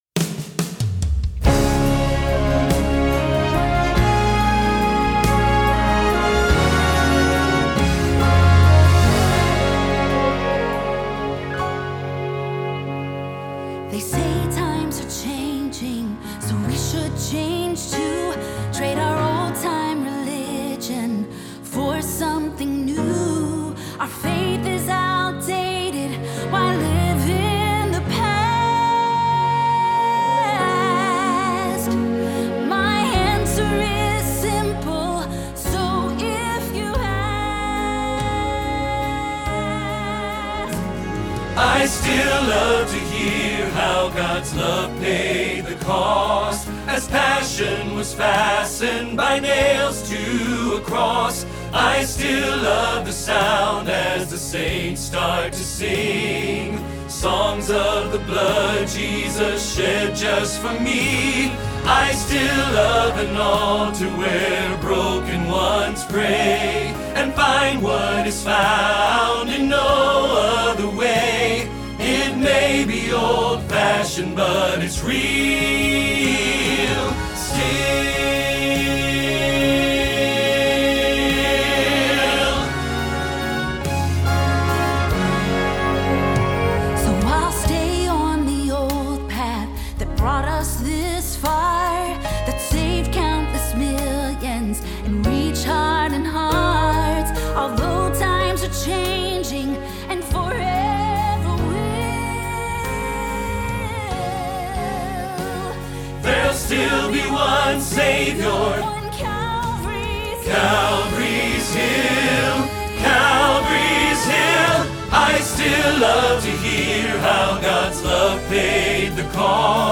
Tenor
Hilltop Choir